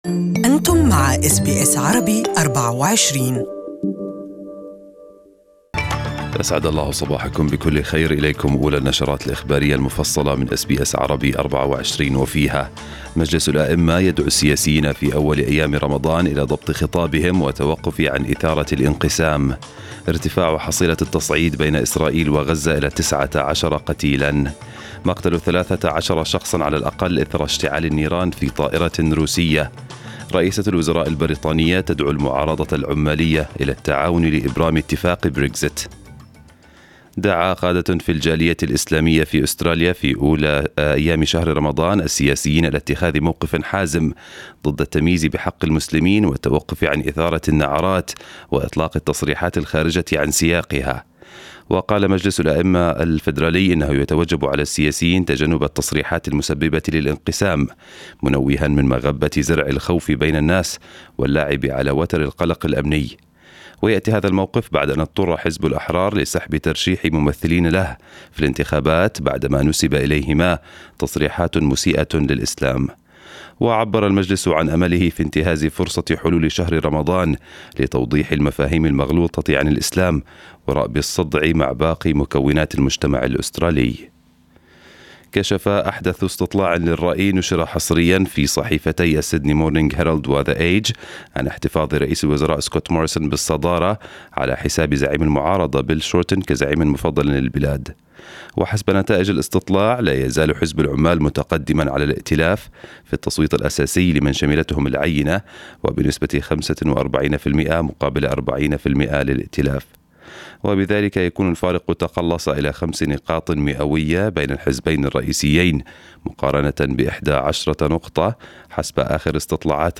Morning news bulletin in Arabic